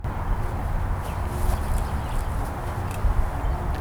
Cemetery Sessions -Digital Voice Recorder Clip 4 2